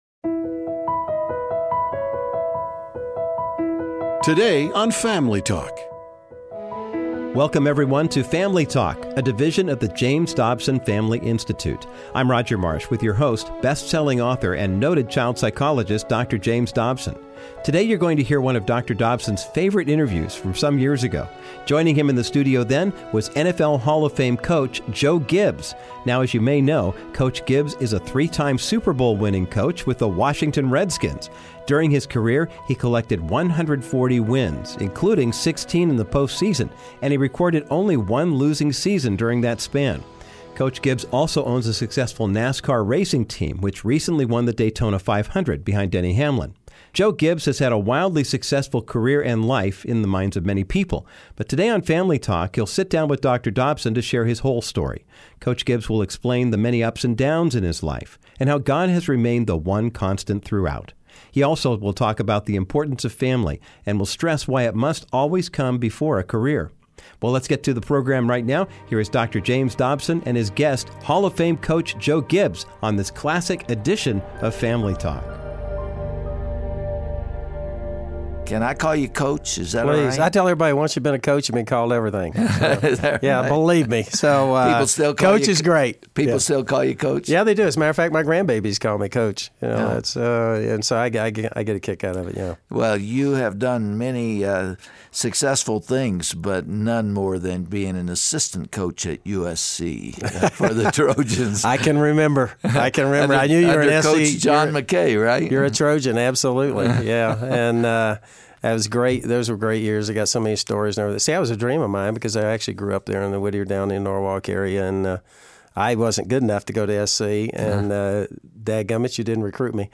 On this timeless Family Talk broadcast, NFL Hall of Fame Coach Joe Gibbs clarifies why worldly accolades are meaningless. He tells Dr. Dobson that despite his achievements, his faith in God propelled him through the tough times of life.